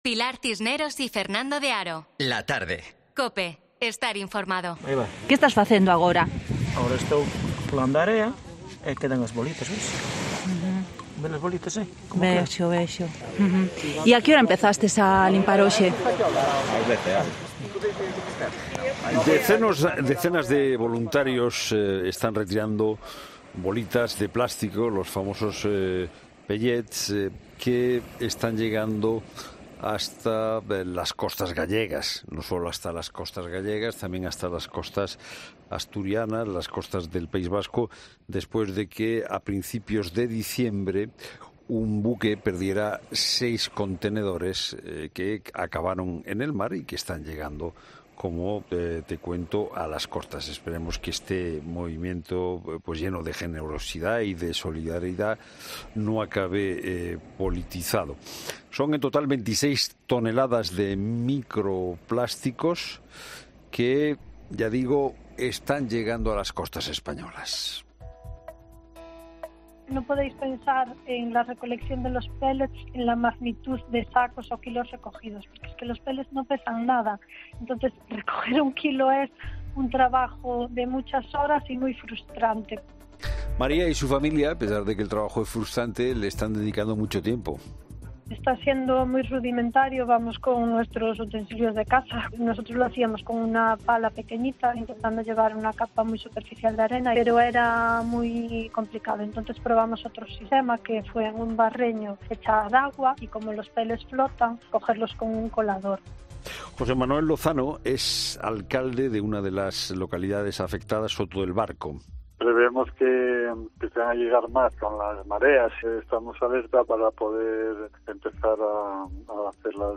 AUDIO: Entrevista a el profesor en el Máster de Derecho Marítimo de la Universidad Católica de Valencia para hablar de las reponsabilidades frente a...